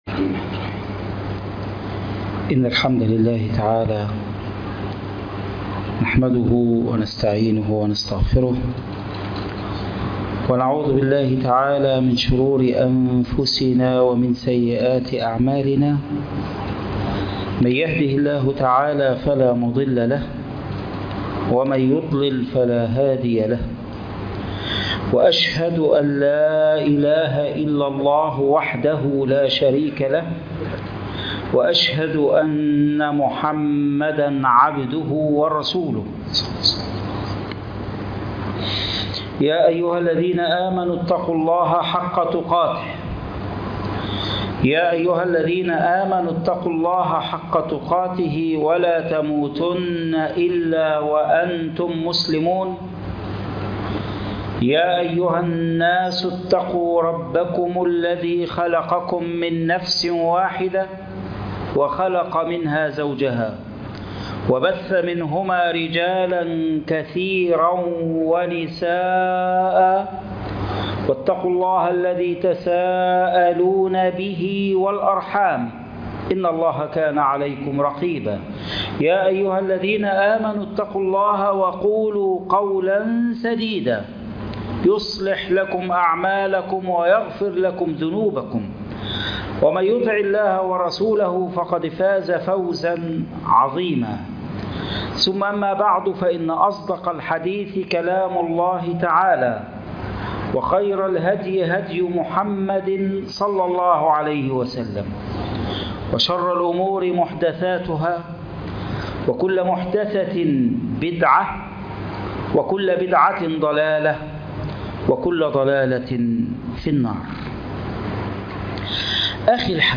على خطى الصحابة 2 - خطبة الجمعة